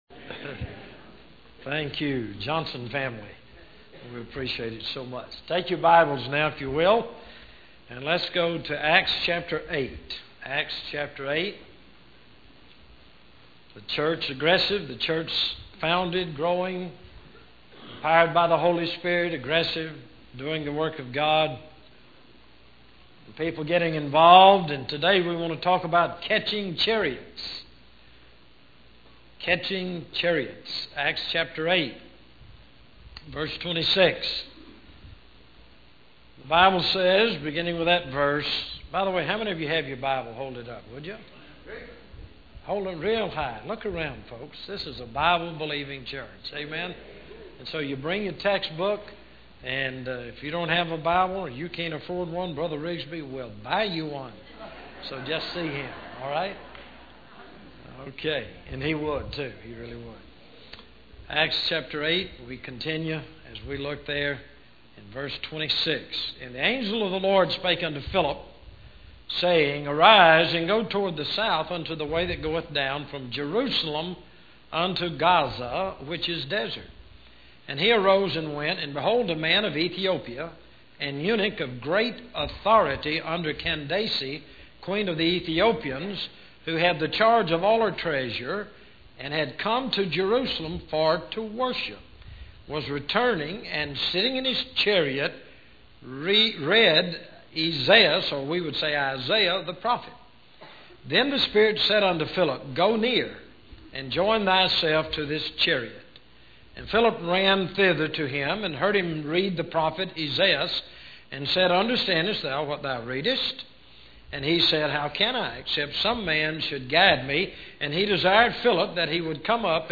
Sermon Audio - Media of Worth Baptist Church